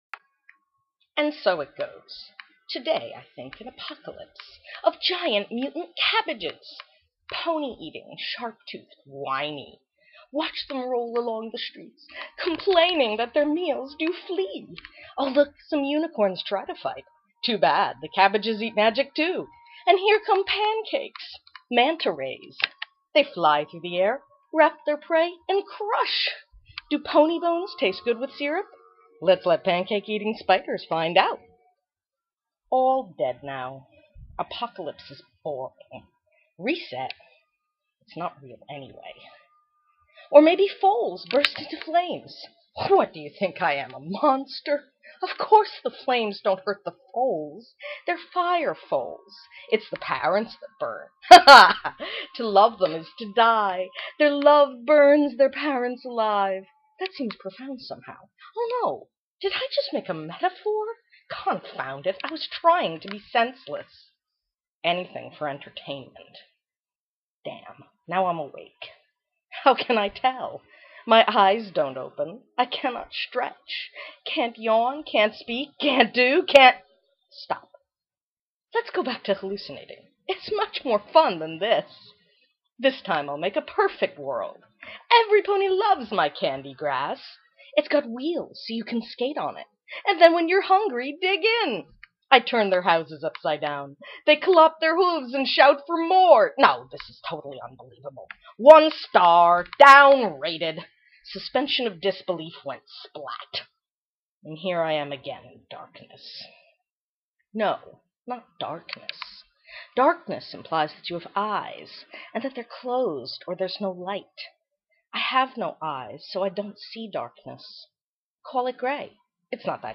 WMA Audio file of reading, by me, now uploaded at my own site.